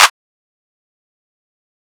Boomin [Clap].wav